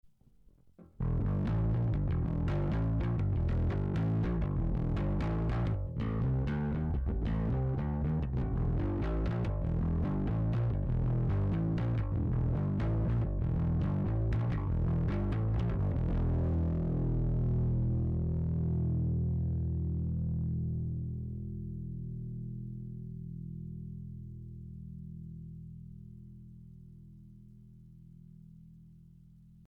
ただ、ベースは『Greco RB580』